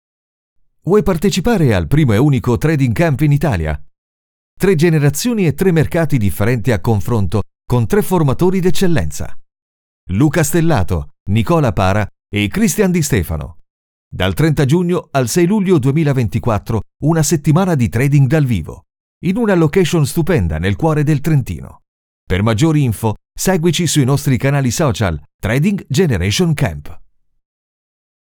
Naturelle, Polyvalente, Fiable, Mature, Douce
Corporate